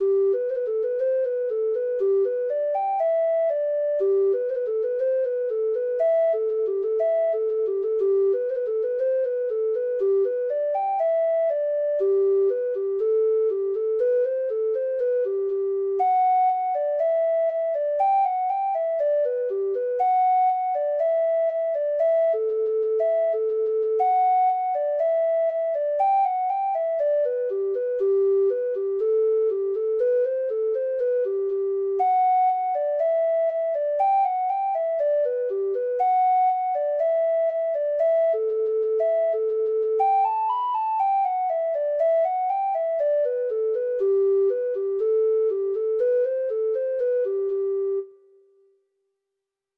Traditional Music of unknown author.
Reels
Irish